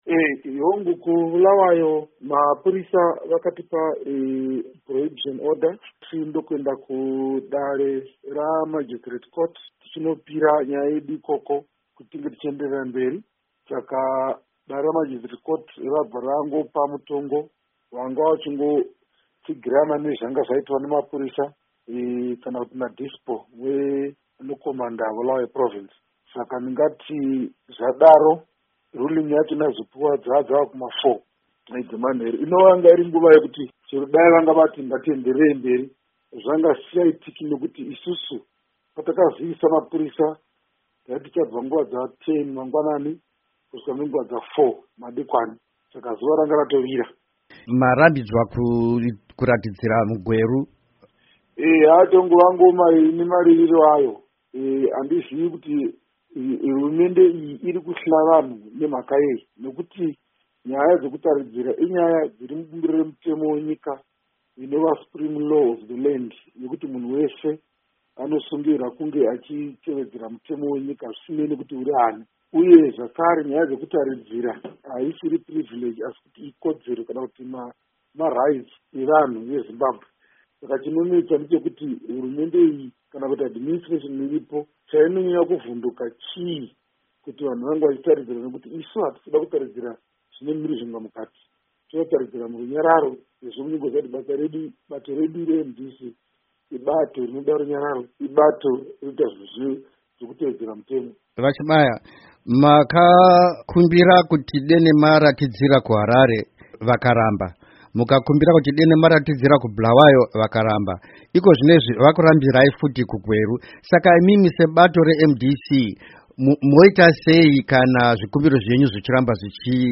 Hurukuro naVaAmos Chibaya